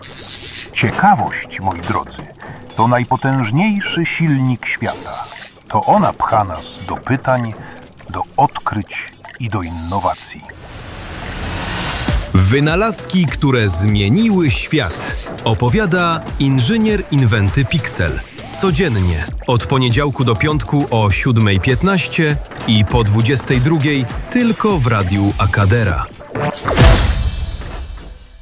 Cykl zrealizowano z wykorzystaniem sztucznej inteligencji, dzięki czemu bohater – inżynier Inwenty Piksel – zyskał unikalny, ciepły i pełen energii głos .